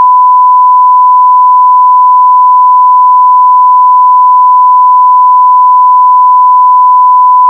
SONAR 3.1 に  1KHz のサイン波を読み込み、それぞれ各周波数に変換した後の波形を見てみました。
↑ これは、上の 変換後の 96KHz データを 44.1KHz へ再変換したものです。